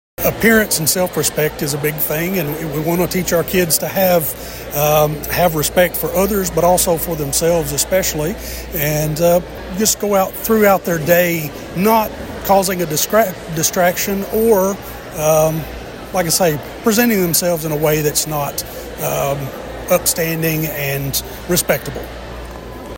Weakley County School board member Jeff Floyd says he believes that parents and children need to take some responsibility.